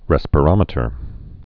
(rĕspə-rŏmĭ-tər)